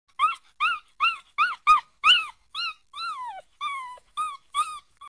Choro De Cachorrinhos Sound Button - Free Download & Play
Dog Barking Sound825 views